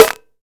Snares
SNARE.63.NEPT.wav